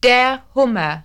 The German for LOBSTER is HUMMER Imagine a LOBSTER with a sense of HUMOUR Please click the button below to hear the correct pronunciation of the word (or click the underlined word above if you cannot see a button below).